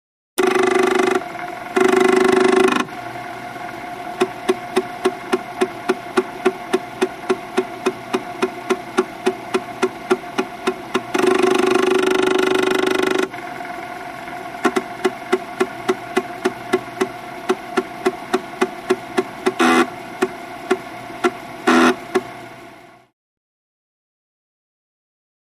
Vintage Floppy Drive | Sneak On The Lot
Vintage Floppy Disk Drive; Read / Write; Floppy Drive; Read / Write / Access / Etc., Hollow Tone, Close Perspective.